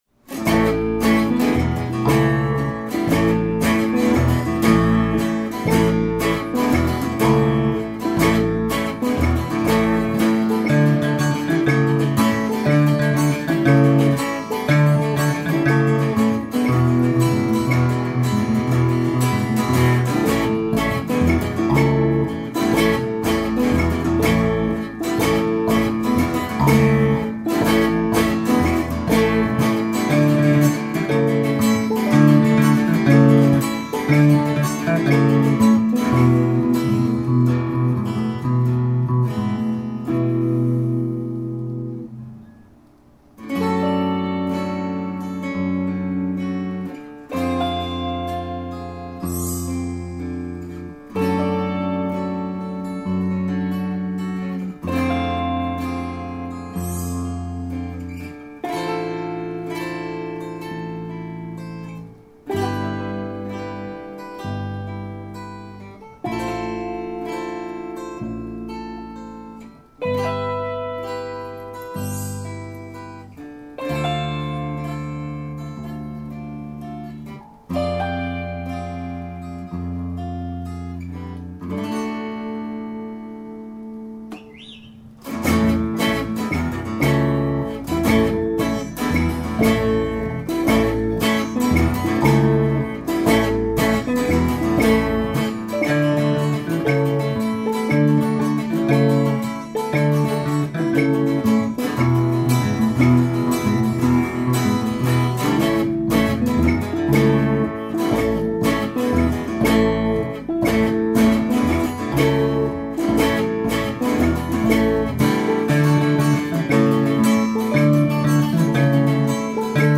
Acoustic
Bass
Electric
Percussion